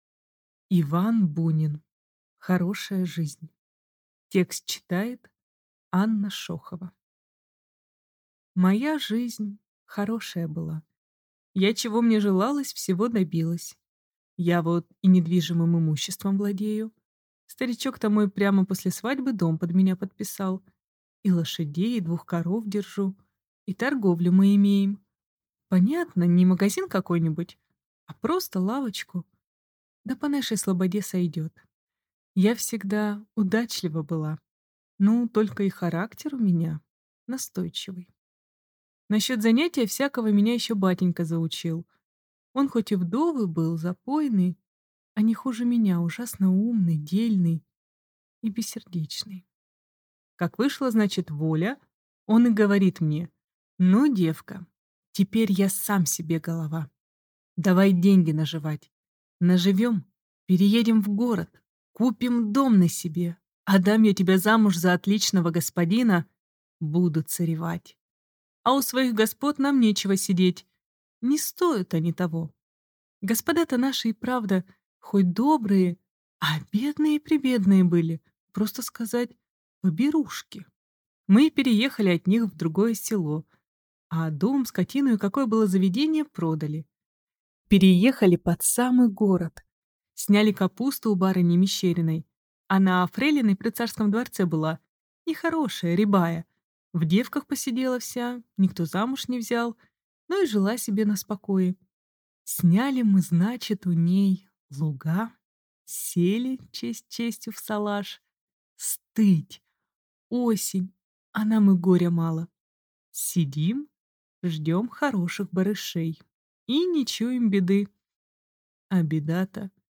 Аудиокнига Хорошая жизнь | Библиотека аудиокниг